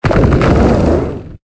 Cri de Géolithe dans Pokémon Épée et Bouclier.